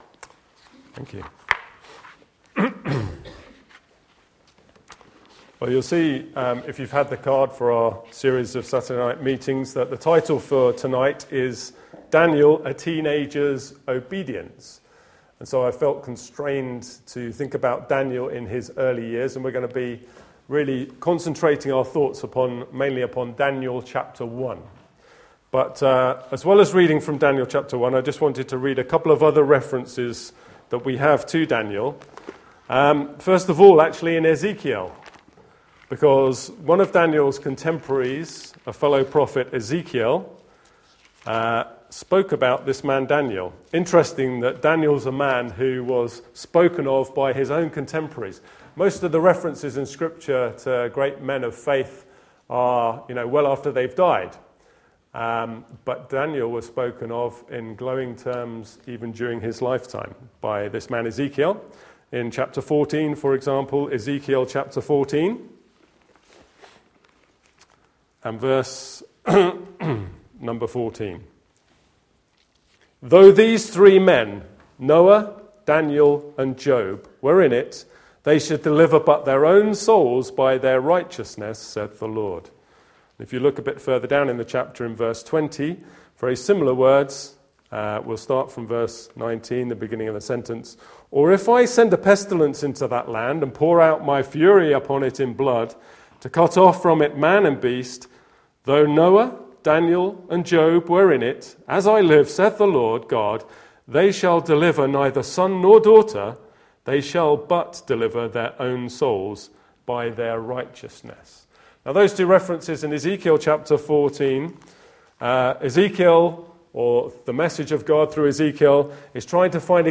You can see tweets from the evening below, as well as listen to the conference: